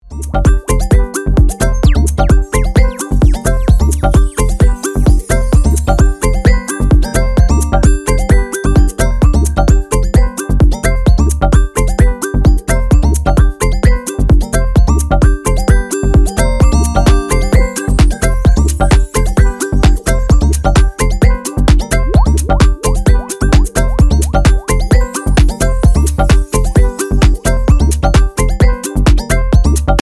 陽気なリフの煌めきが眩いゲーミングハウス
さりげなく複雑で繊細なエフェクトの妙味が完全に新世代の感覚